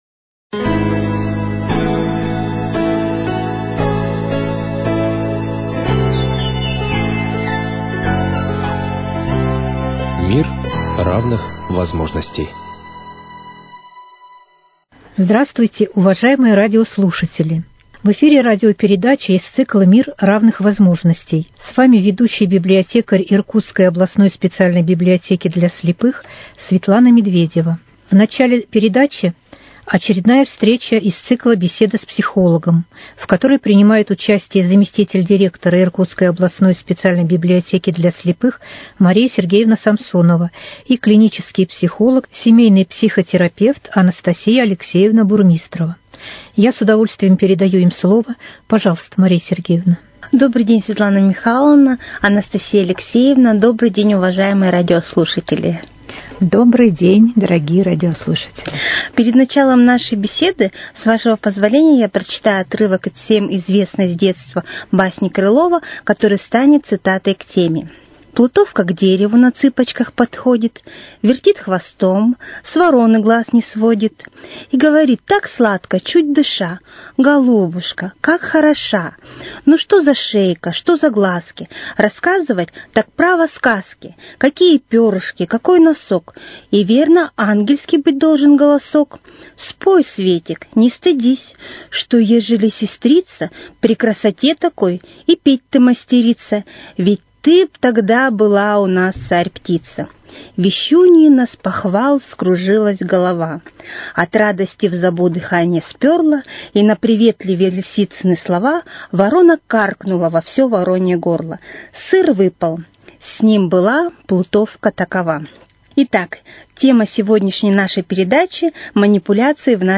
Цикл передач – совместный проект Иркутского радио и Иркутской Областной библиотеки для слепых.